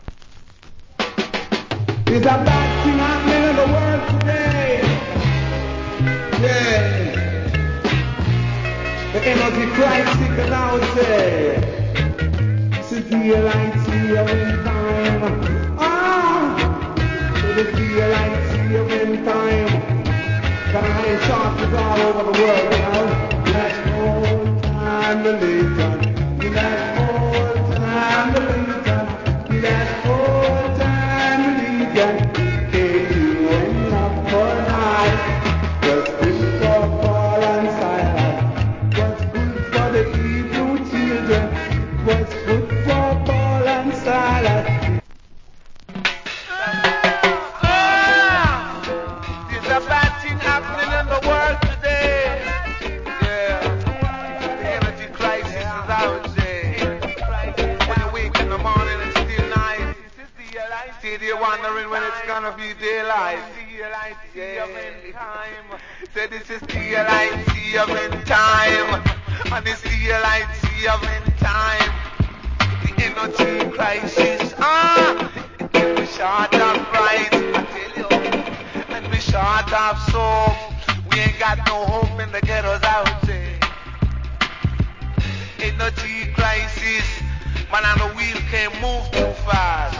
Nice DJ.